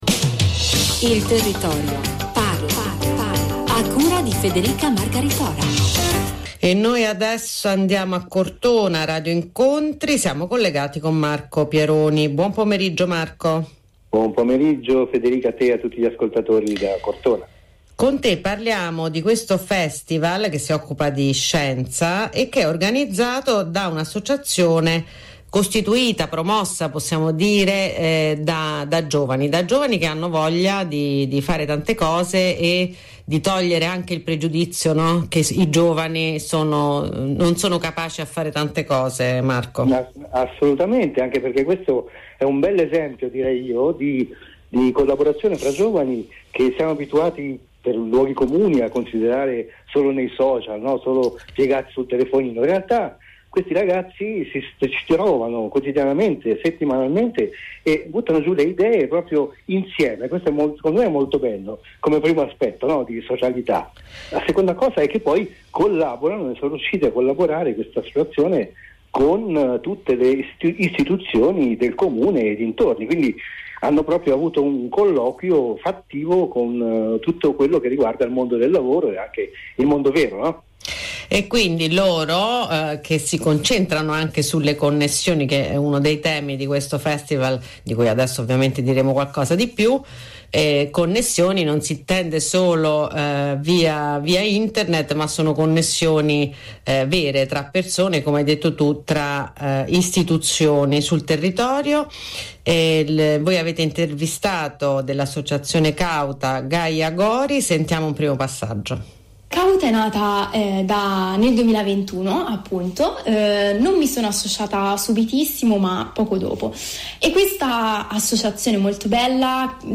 Uno speciale intervista andata in onda su Radio Incontri inBlu e su inBlu2000 la radio Nazionale